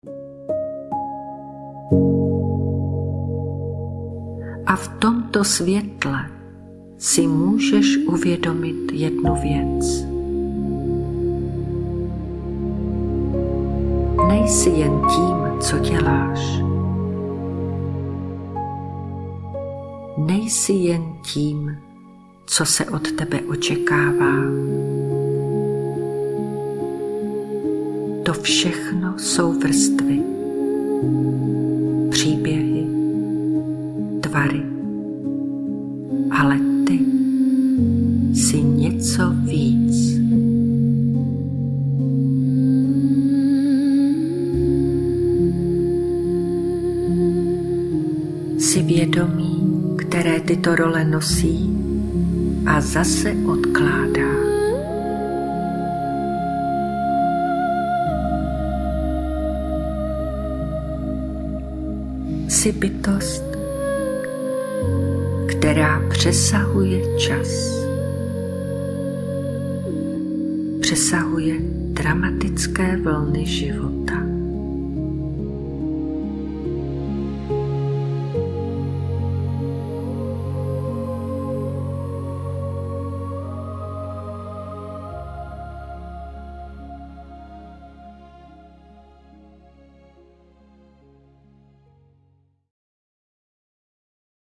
obsahuje čtyři vedené meditace, které na sebe jemně navazují, ale můžeš je používat i samostatně podle aktuální potřeby.
Brána tichameditace pro hlubokou relaxaci